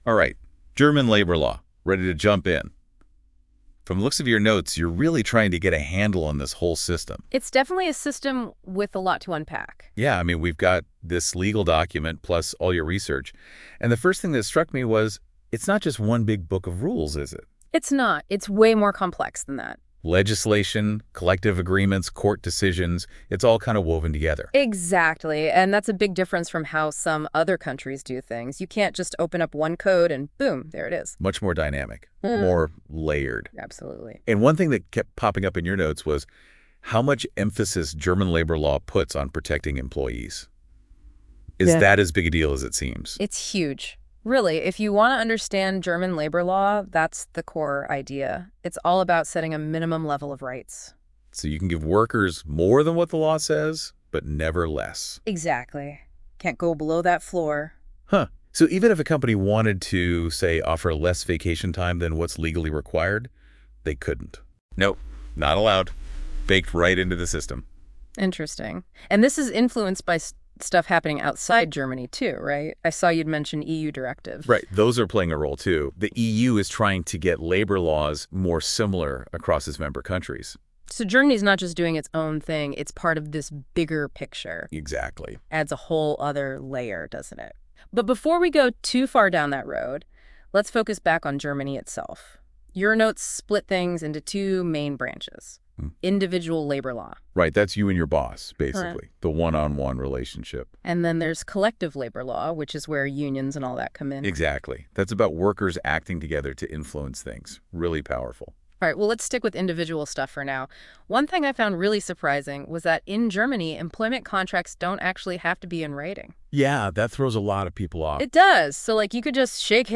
Listen to our AI-generated podcast, based on the Hogan Lovells Client Note: